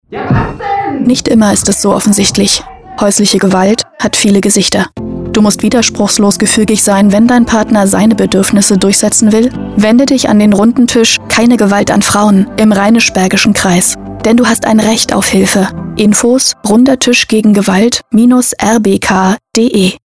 Funkspots: Sie werden im Aktionszeitraum wieder bei Radio Berg zu hören sein!